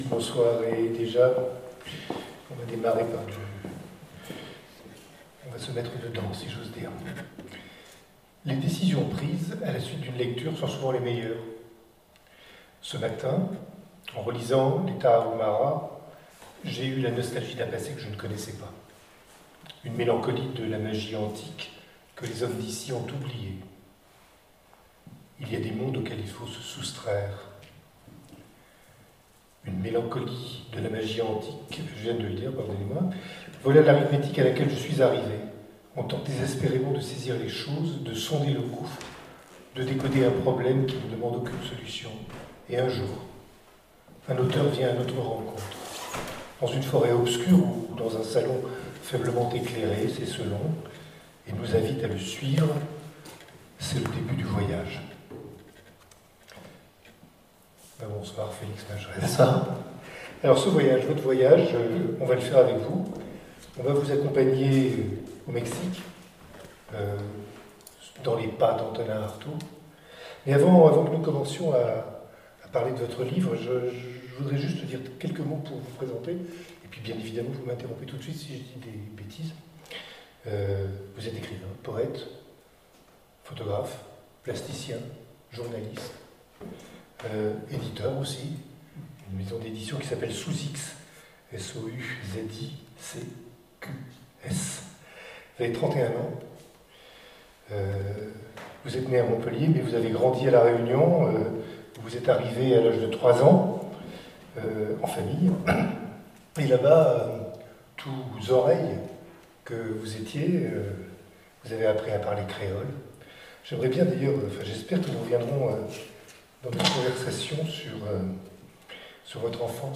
Au Pays des Rêves noirs - entretien 1